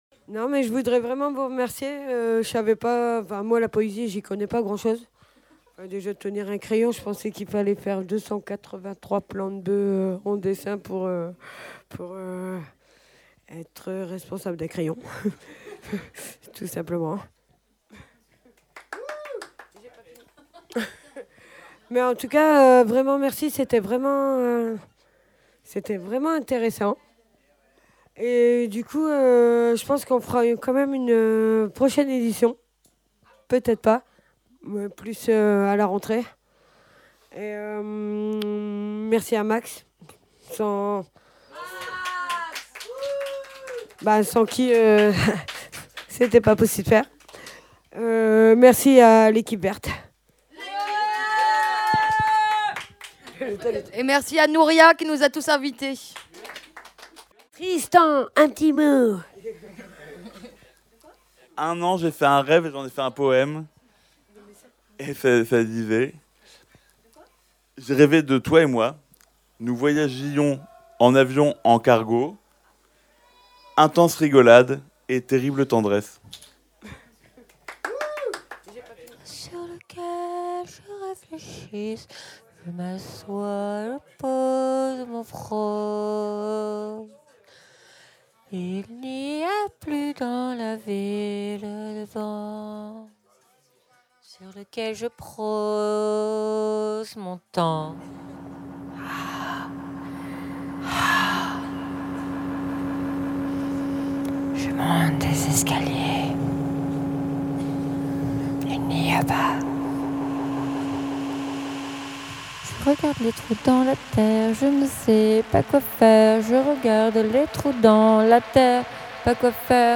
Voilà le discours de clôture du week-end poétique qui a eu lieu à Rue des Gardes en mai 2022.